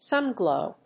アクセント・音節sún・glòw